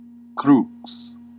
Ääntäminen
US : IPA : [krɑs]